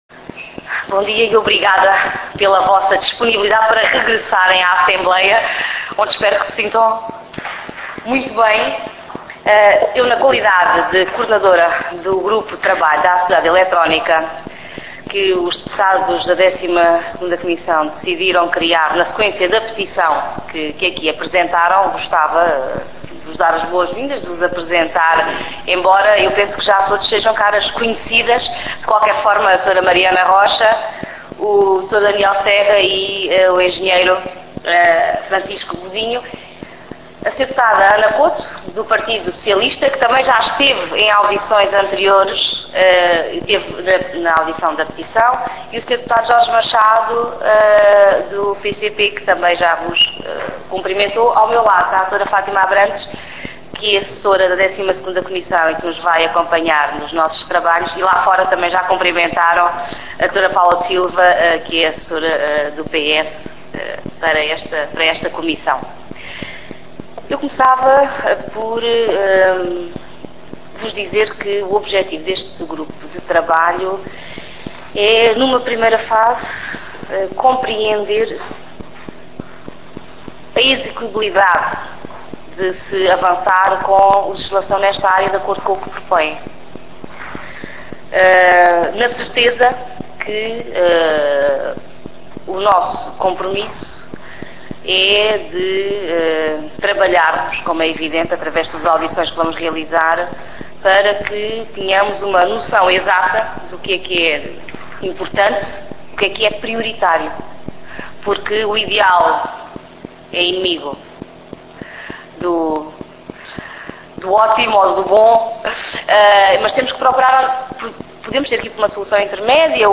audição na Assembleia da República